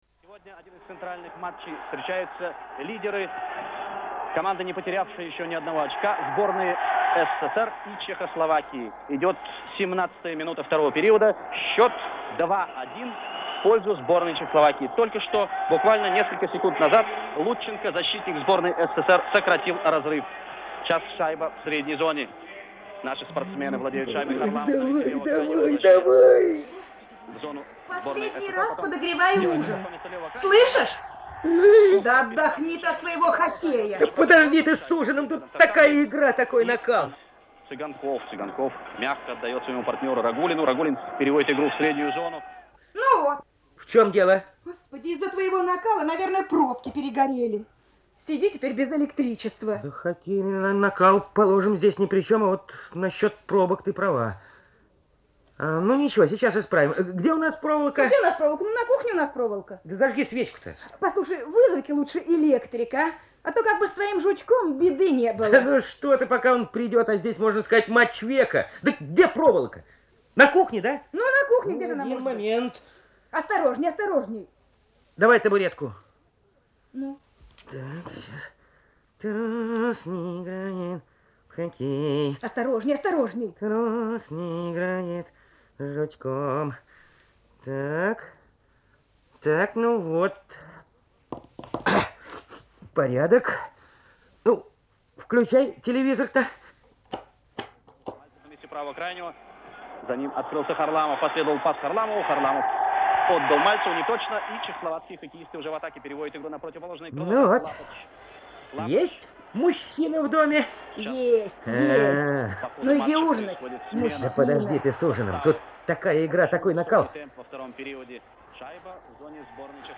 Социальная реклама в СССР
Это были оригинальные студийные ленты на бобышках. Это социальная реклама советского времени на тему противопожарной безопасности.